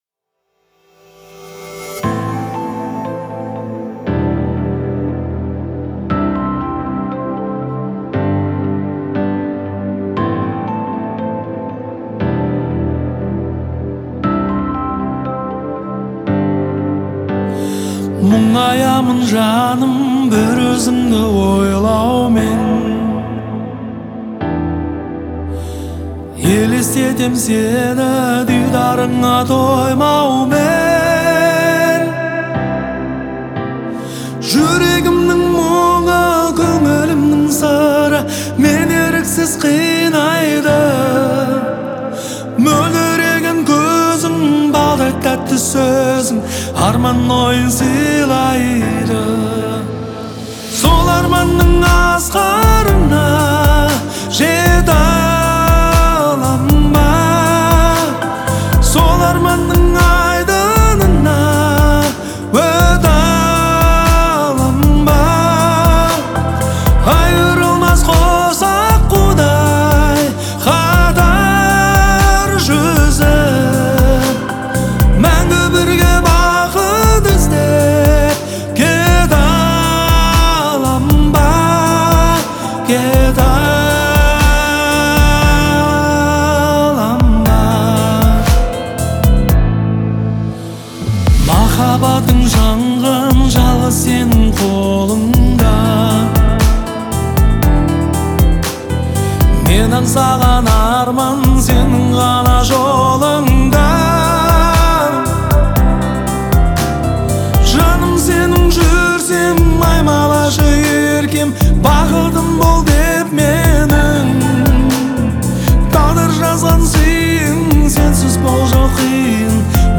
это трогательный и мелодичный трек в жанре поп-музыки
Исполнение наполнено эмоциями
звучит искренне и тепло